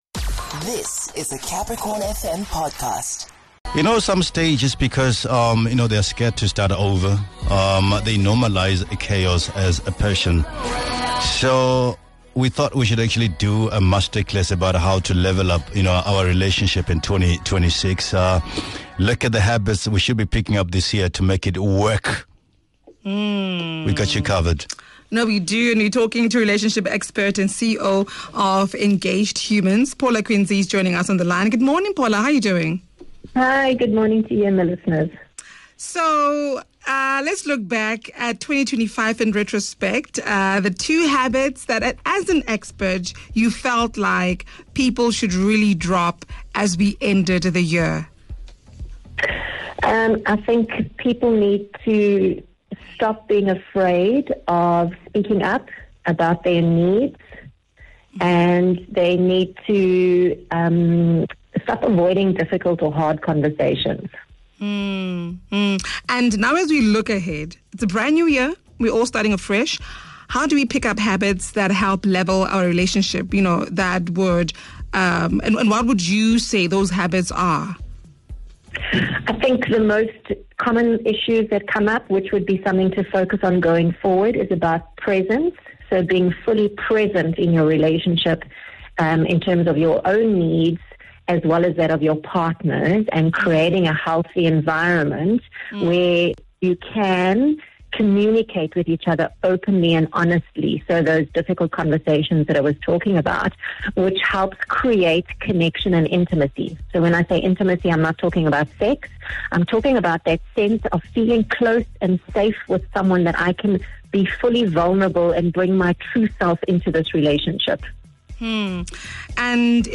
If you feel like your relationship is stuck in the same place and would like to either level it up or let it go this year, this conversation is for you.